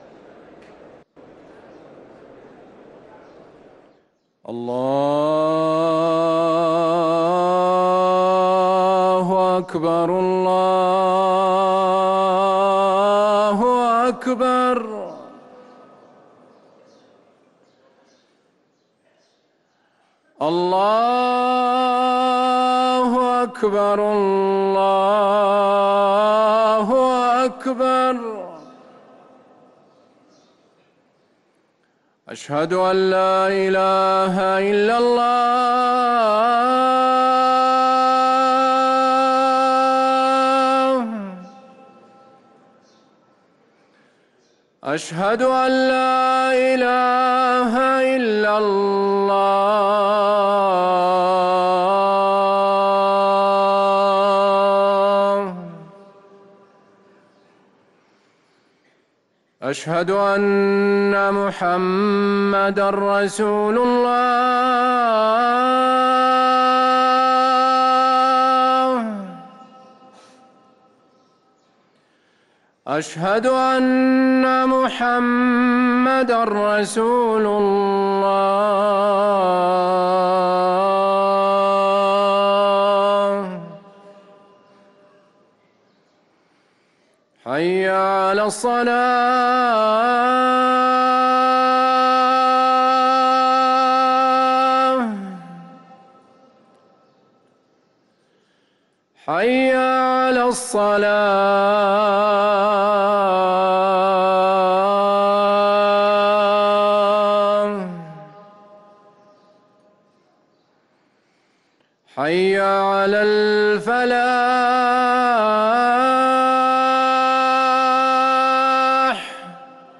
أذان الظهر